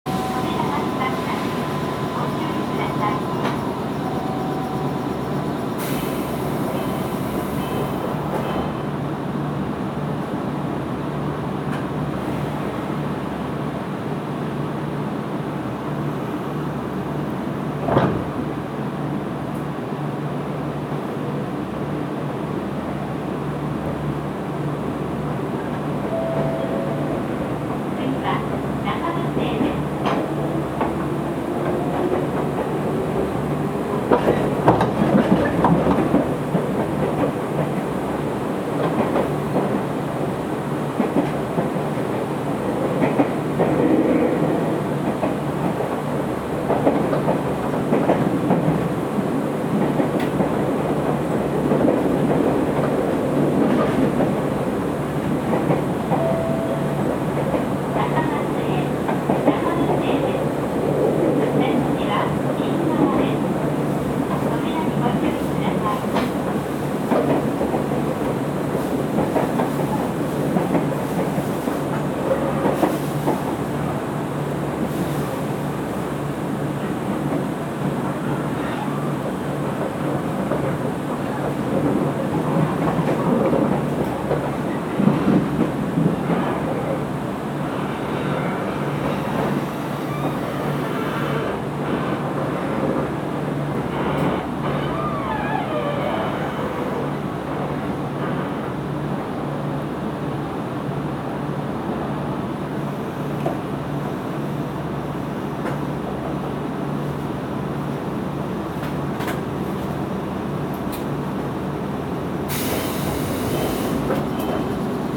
走行機器は超多段式バーニア抵抗制御となっています。
走行音
録音区間：東松江～中松江(お持ち帰り)